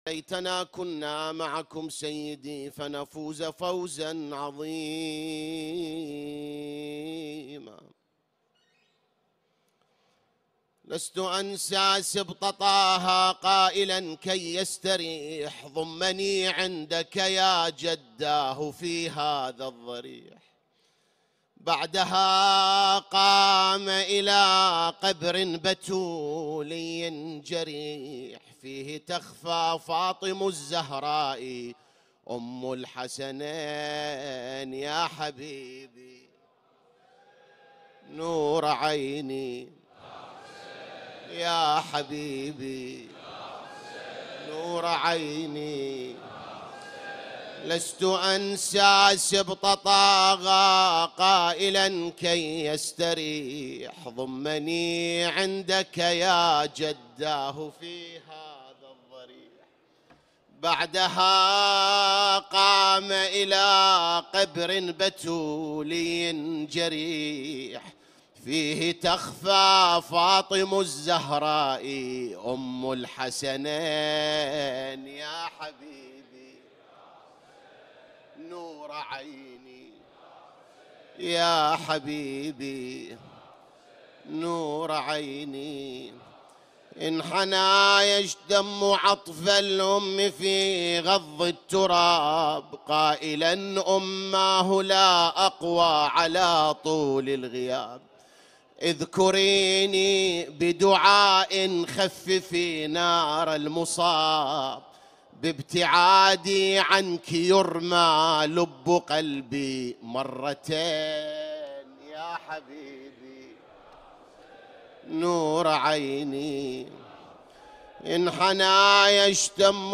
3 محرم 1447 هـ || من الصحن الحسيني